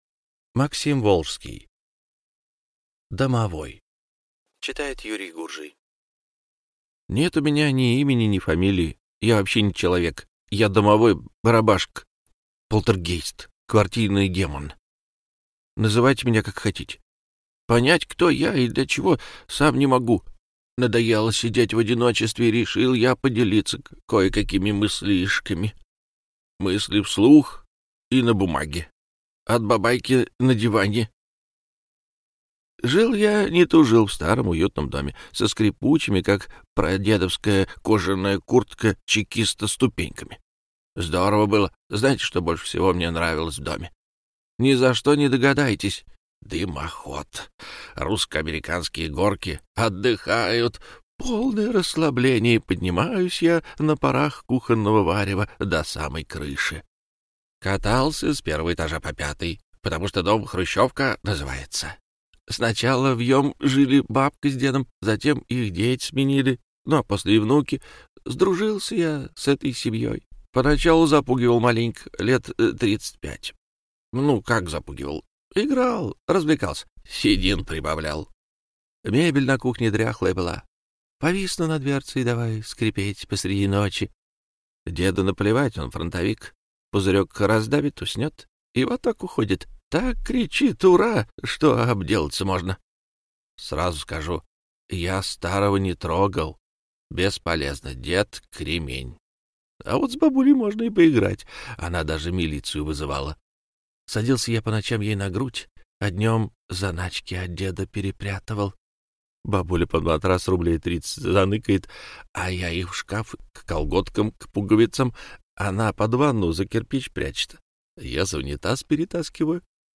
Аудиокнига Домовой | Библиотека аудиокниг